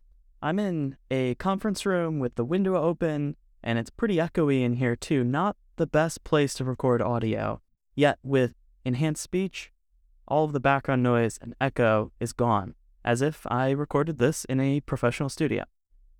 sample_enhanced_speech.wav